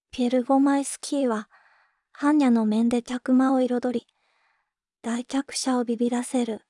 voicevox-voice-corpus
voicevox-voice-corpus / ROHAN-corpus /WhiteCUL_かなしい /ROHAN4600_0012.wav